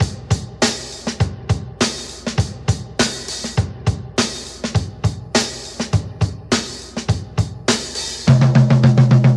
102 Bpm '60s Drum Loop Sample G Key.wav
Free drum groove - kick tuned to the G note.